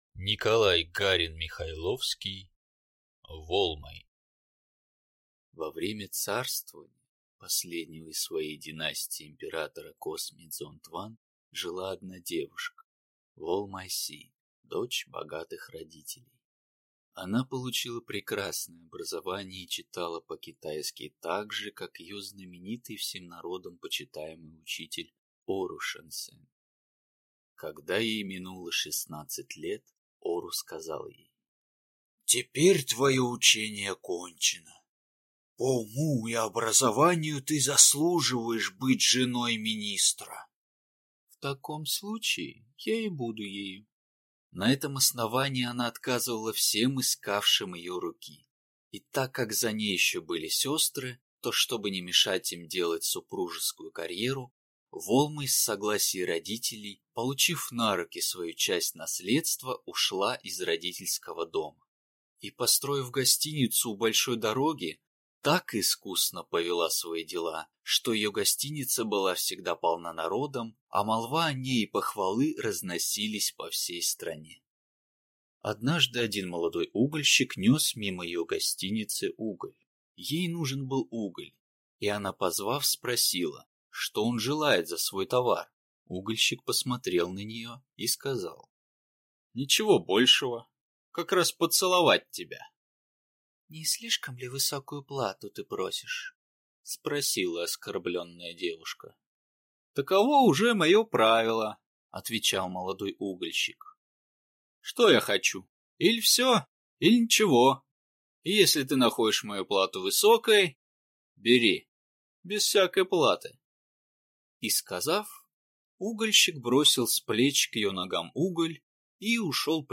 Аудиокнига Волмай | Библиотека аудиокниг